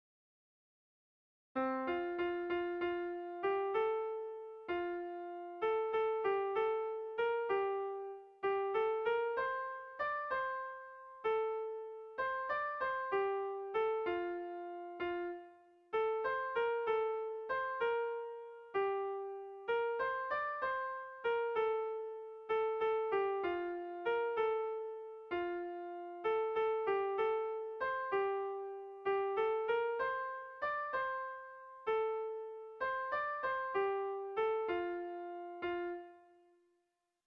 Bertso melodies - View details   To know more about this section
ABDA2B